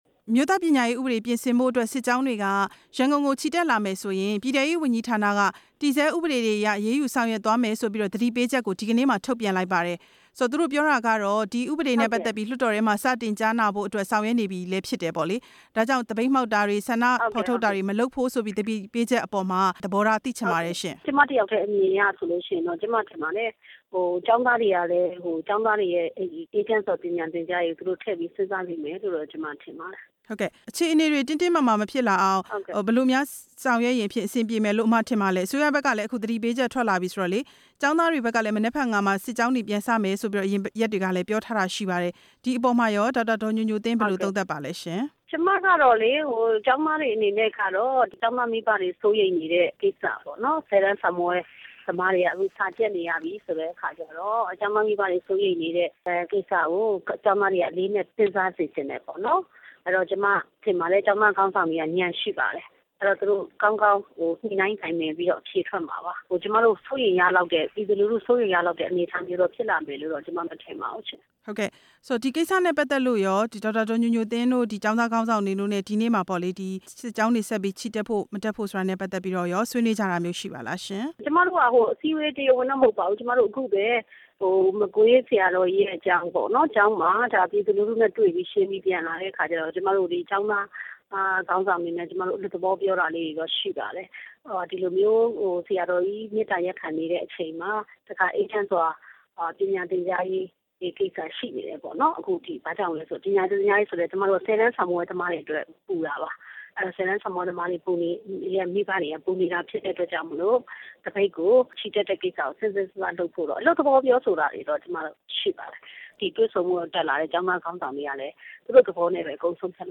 ဒေါက်တာ ညိုညိုသင်းနဲ့ မေးမြန်းချက်